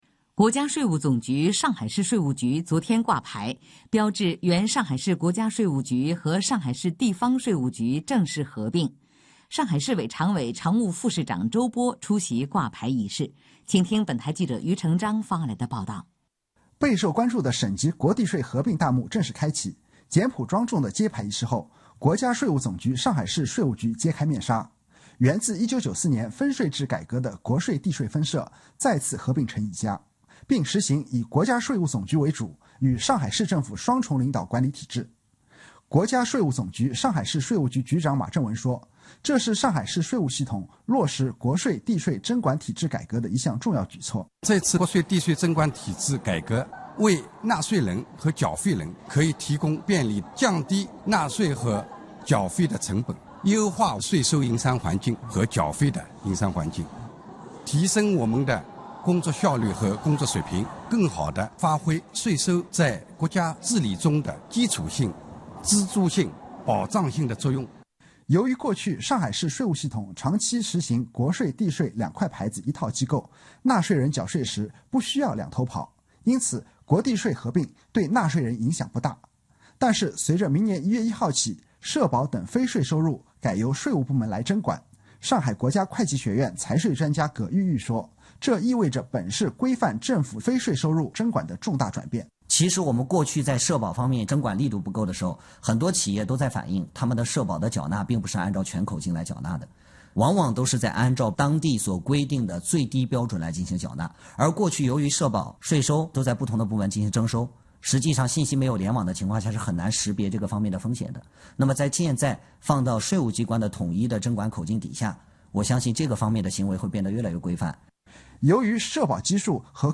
2018年6月15日，SMG广播新闻中心